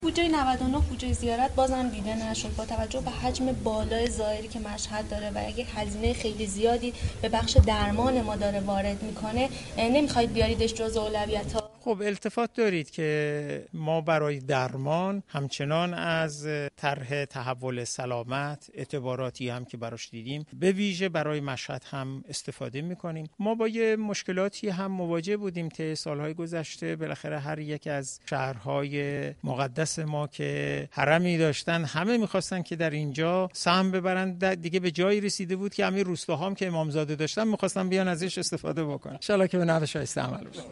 به گزارش خبر رادیو زیارت ، محمدباقر نوبخت در پاسخ به سوال خبرنگار ما مبنی بر علت حذف بودجه زیارت از بودجه 99 گفت : در سال های گذشته هر یک از شهرهایی که حرم و امامزاده ای داشتند همه می خواستند سهمی از بودجه زیارت ببرند و حتی روستاهایی که امامزاده داشتند نیز می خواستند از این بودجه بهره مند شوند به همین دلیل ما بودجه زیارت را حذف کردیم.